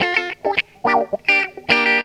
GTR 83 GM.wav